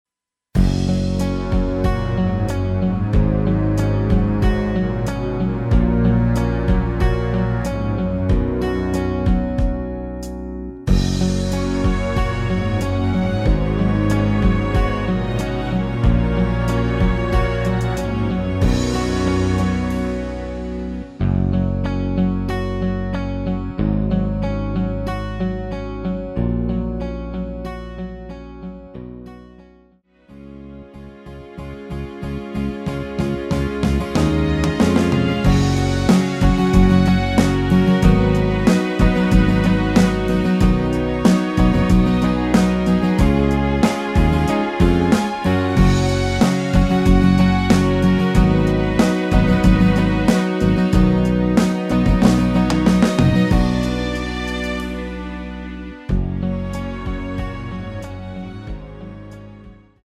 내린 MR입니다.
F#
음정은 반음정씩 변하게 되며 노래방도 마찬가지로 반음정씩 변하게 됩니다.
앞부분30초, 뒷부분30초씩 편집해서 올려 드리고 있습니다.
중간에 음이 끈어지고 다시 나오는 이유는
곡명 옆 (-1)은 반음 내림, (+1)은 반음 올림 입니다.